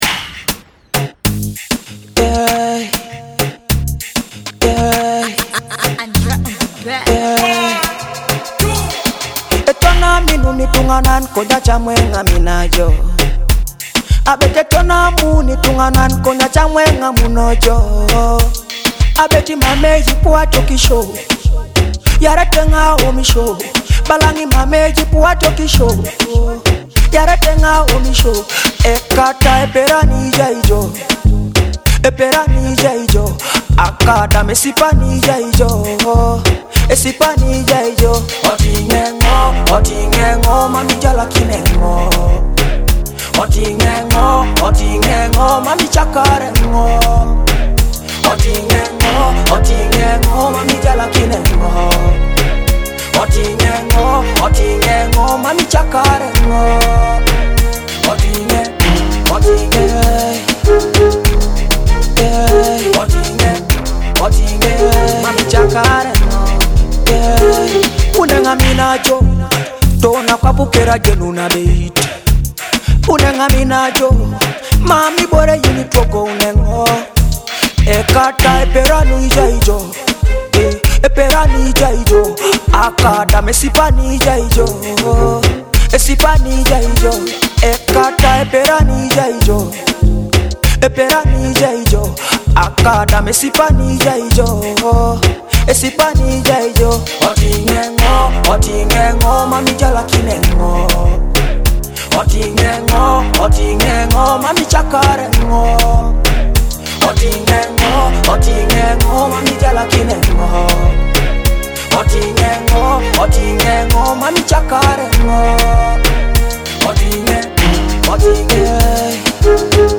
blends rich Teso music with modern Afrobeat energy.